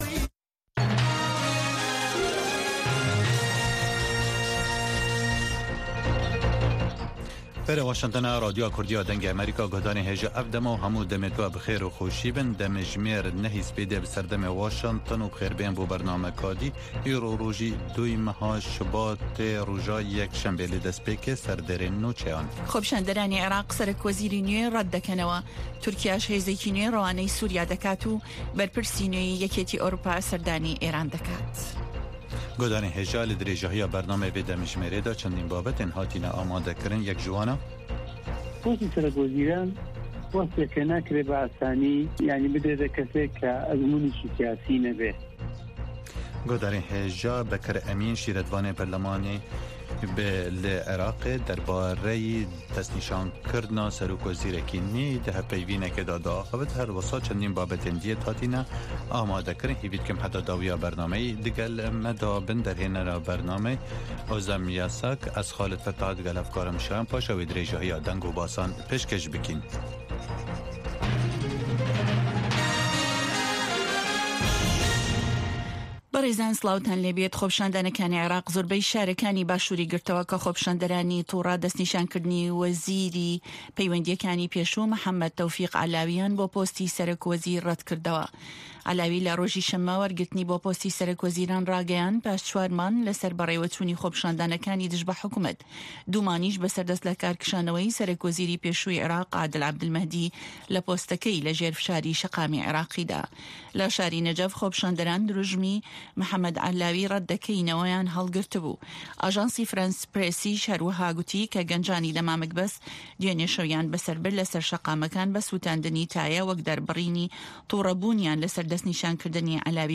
هه‌واڵه‌کان ، ڕاپـۆرت، وتووێژ، مێزگردی هه‌فته‌.